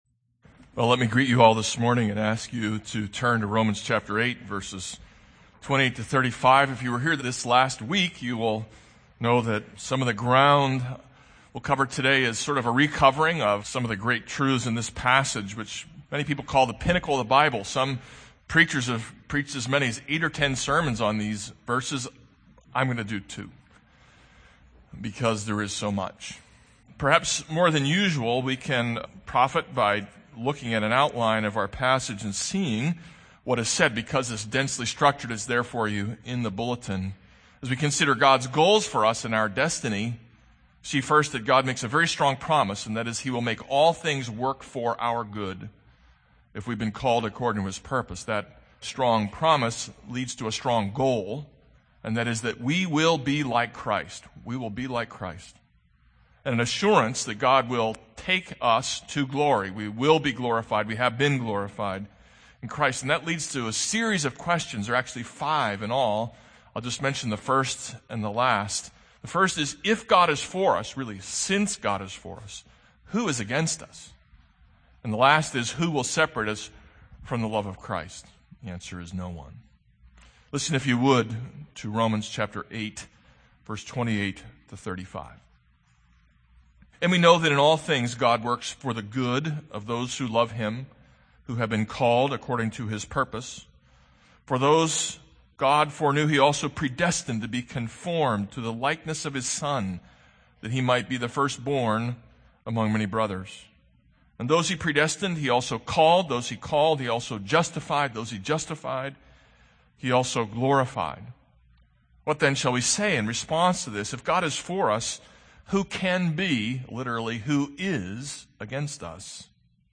This is a sermon on Romans 8:28-35.